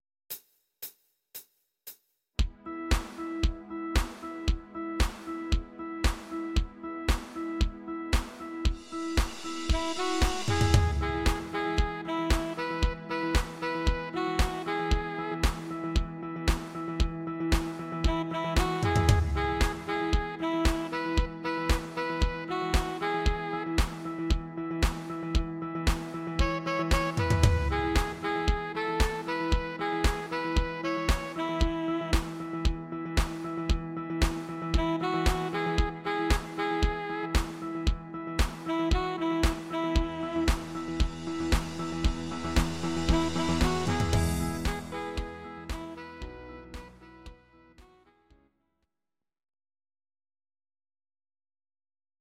Audio Recordings based on Midi-files
Our Suggestions, Pop, German, 2010s